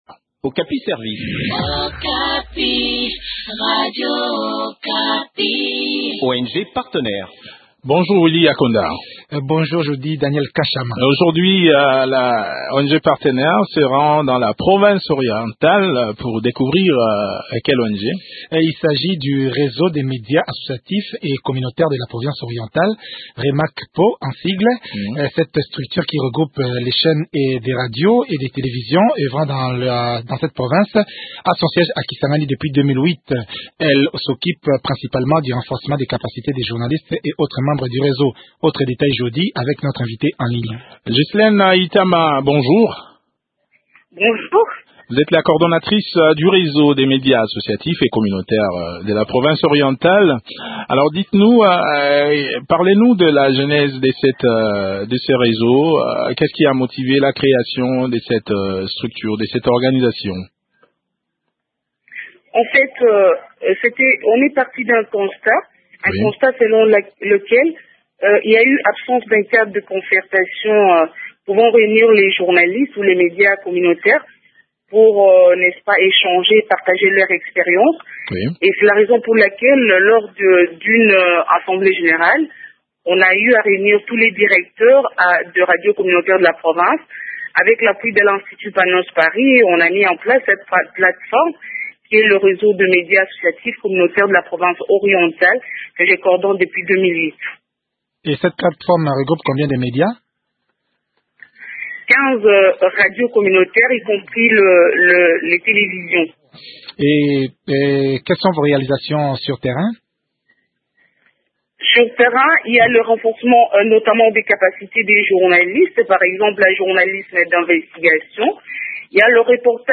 Le point sur les activités de ce réseau dans cet entretien